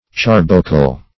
Search Result for " charbocle" : The Collaborative International Dictionary of English v.0.48: Charbocle \Char"bo*cle\, n. Carbuncle.
charbocle.mp3